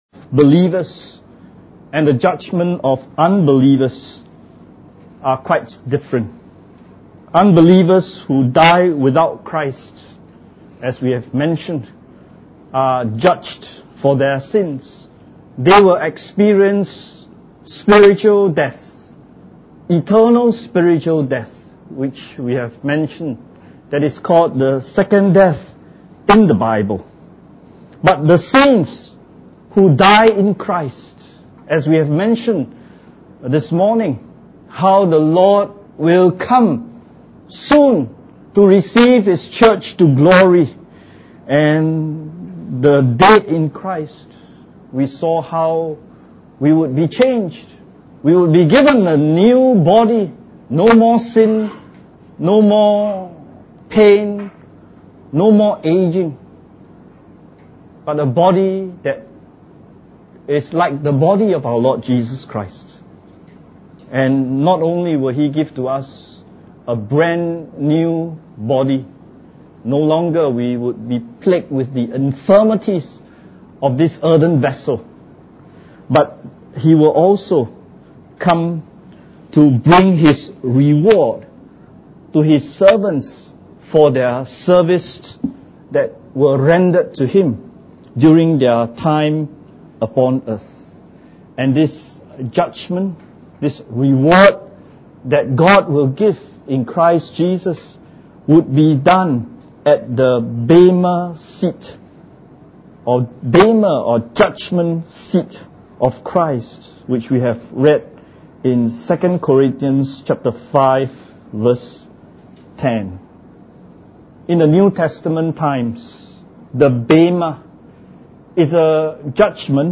Church Camp 2015 Looking for That Blessed Hope – Reward (The True Riches) Message 4